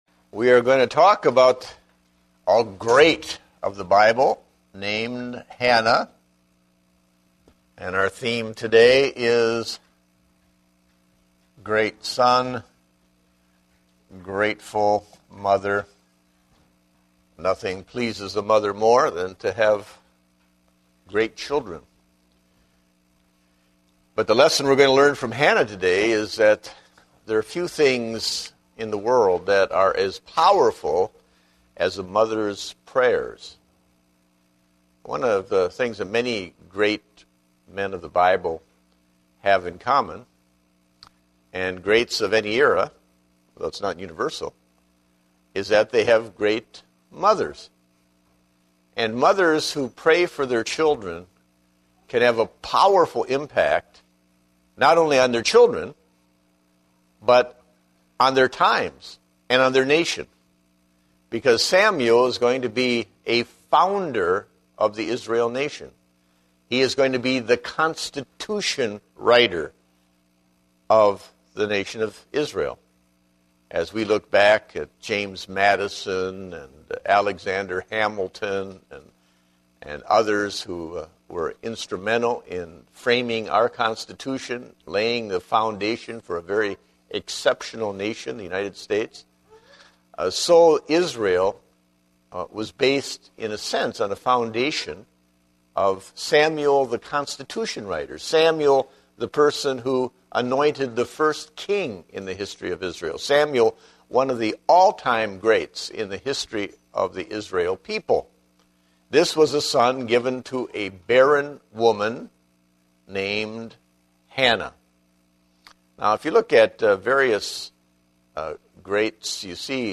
Date: November 7, 2010 (Adult Sunday School)